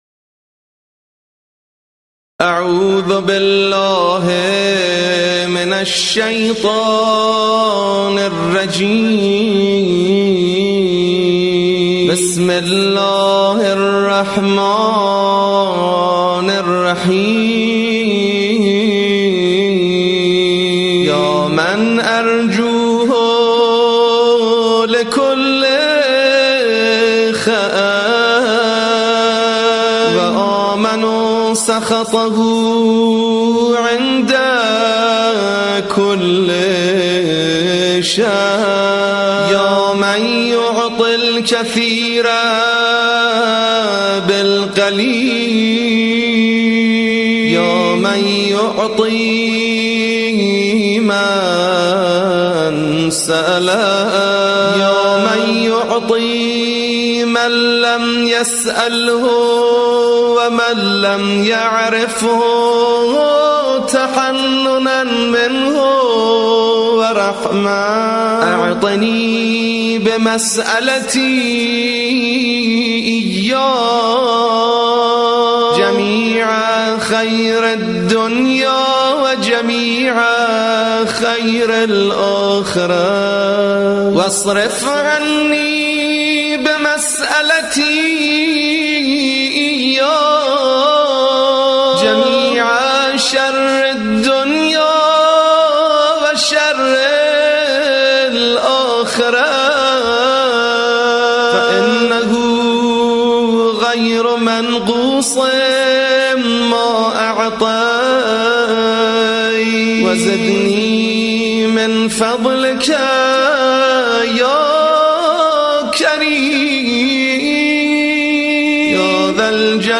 شهر رجب: دعاء يا من أرجوه لكل خير بصوت حزين جدا mp3